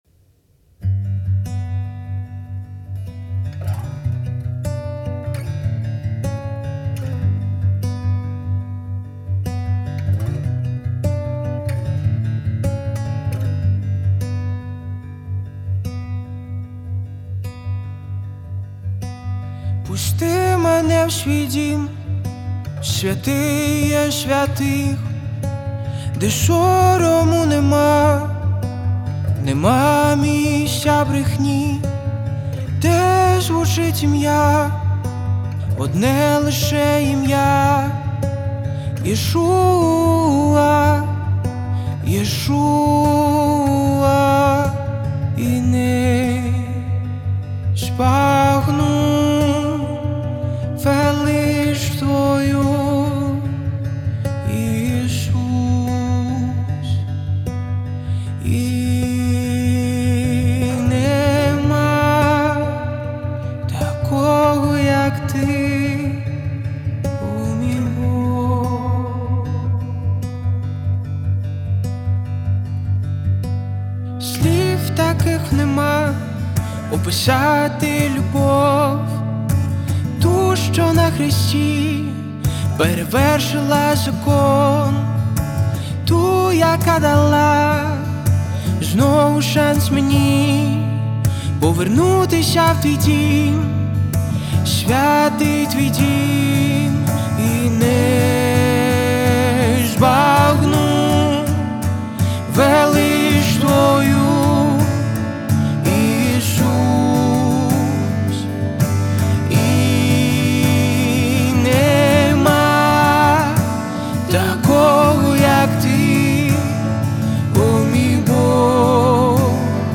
486 просмотров 122 прослушивания 15 скачиваний BPM: 166